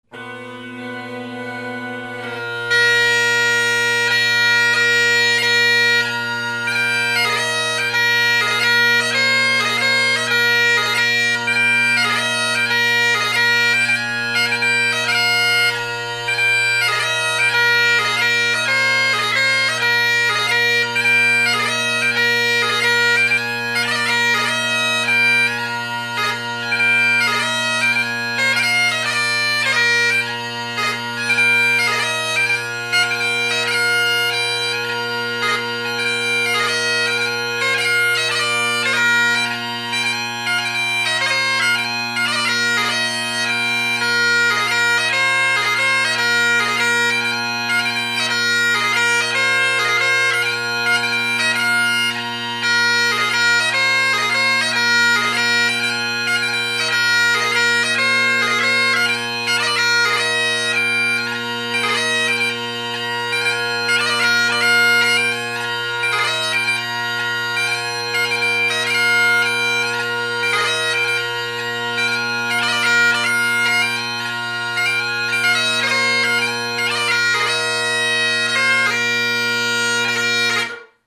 played slower